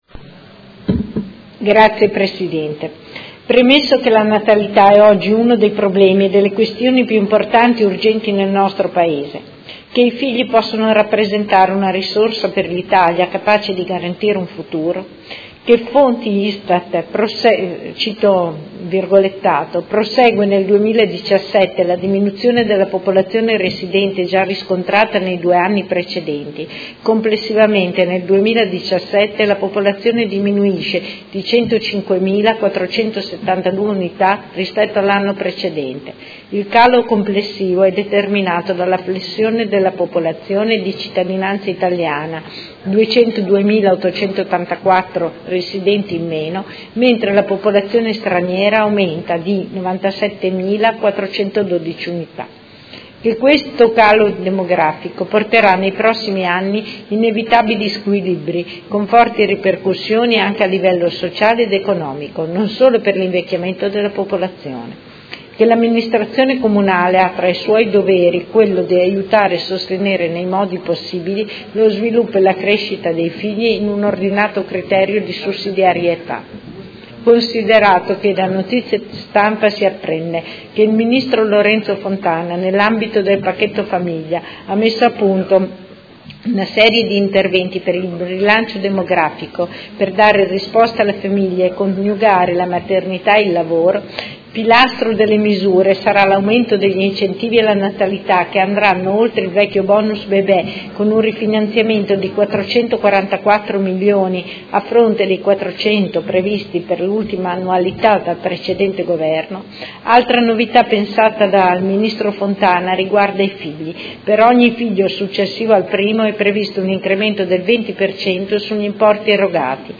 Seduta del 20 dicembre 2018